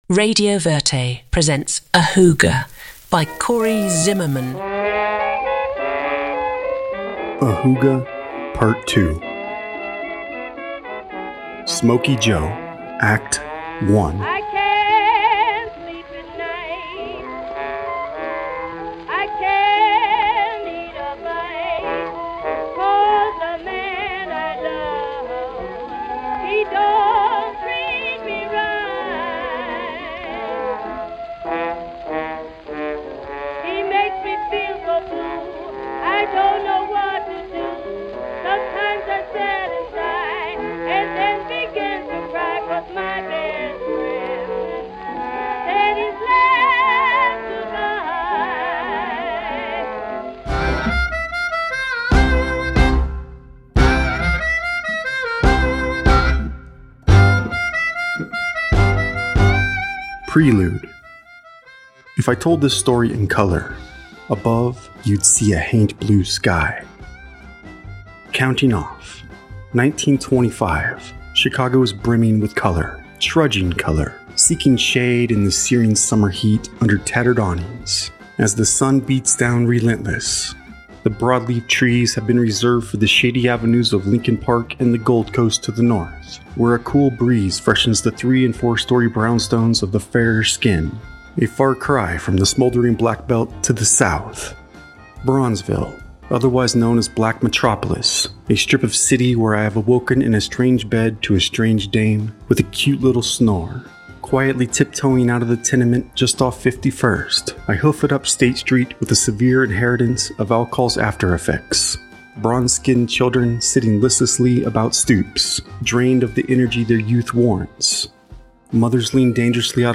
Library of Audio Fiction Podcasts
AHOOGA! | Trailer Tue, 27 Aug 2024 03:22:12 GMT AHOOGA! begins as a coming-of-age tale set in rural America. The protagonist, a young boy named Oscar, grapples with his father's strict rules and expectations.